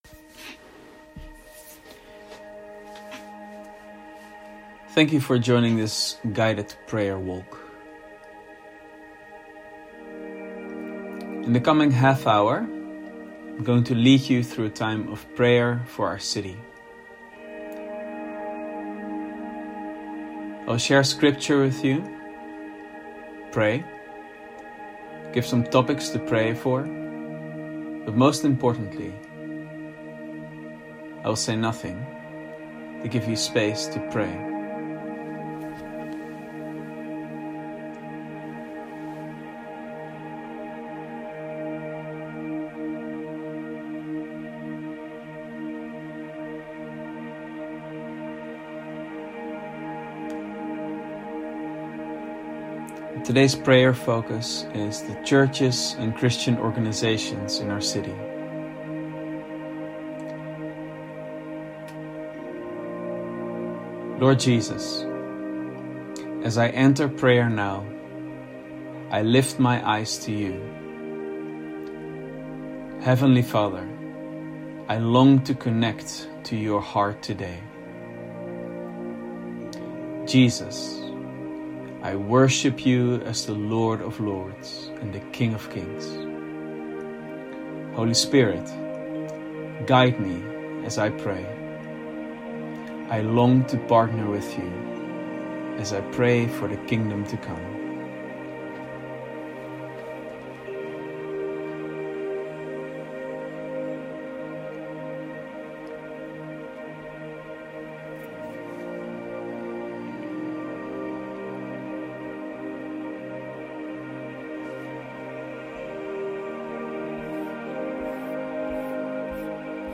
Guided_Prayer_Walk_1.mp3